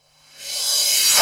Reverse Crash.wav